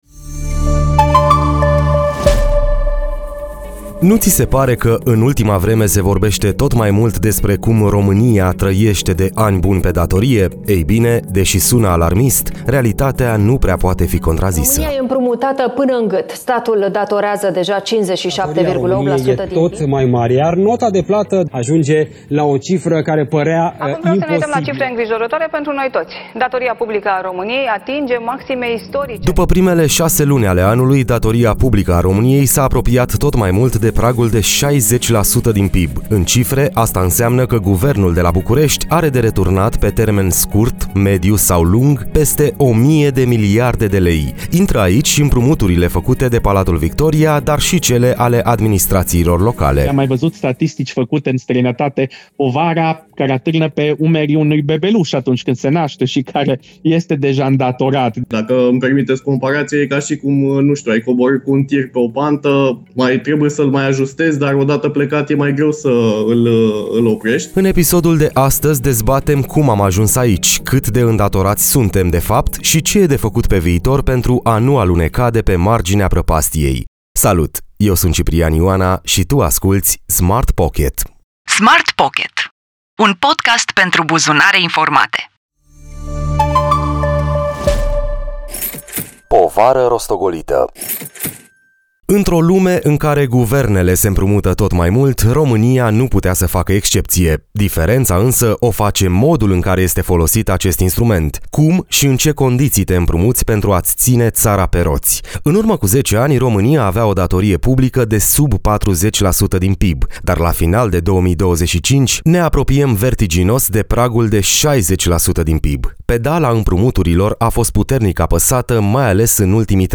Smart Pocket, un podcast care îți arată că banii contează cu adevărat doar atunci când îi înțelegi.